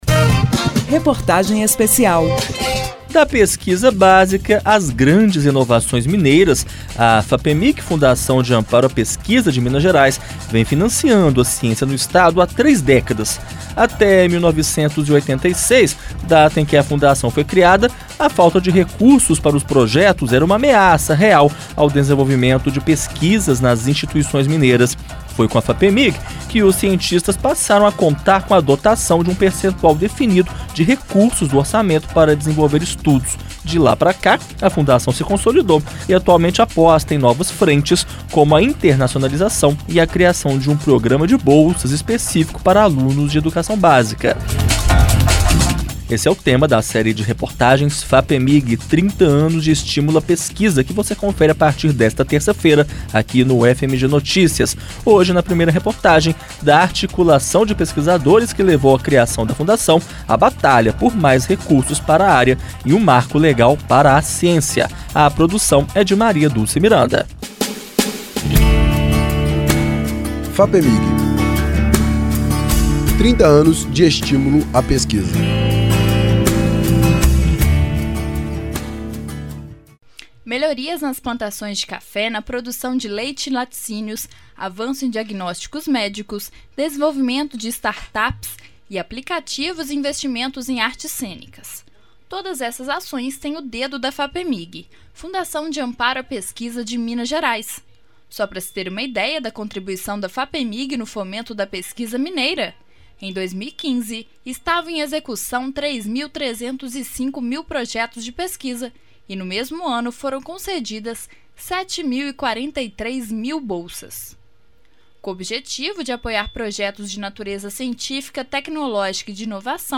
MAT�RIA 01: Da articula��o de pesquisadores que levou a cria��o da funda��o, a batalha por mais recursos para a �rea e o marco legal para a ci�ncia, � o tema da primeira reportagem.